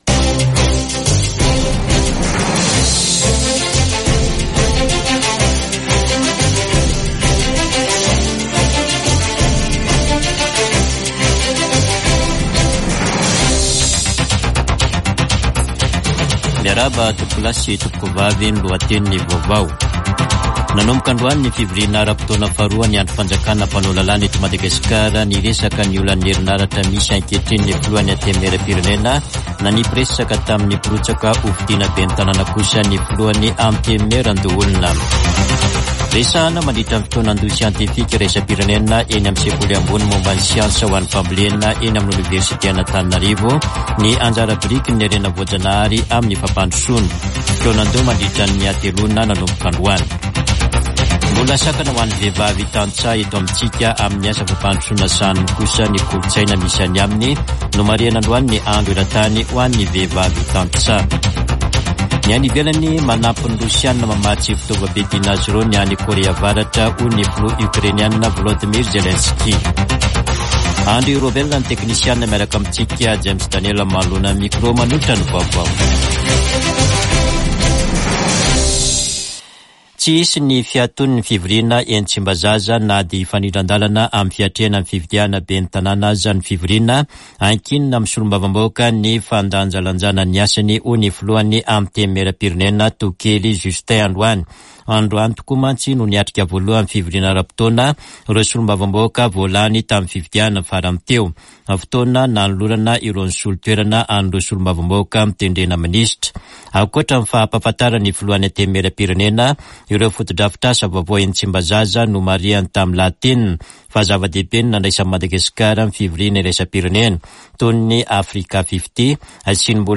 [Vaovao hariva] Talata 15 ôktôbra 2024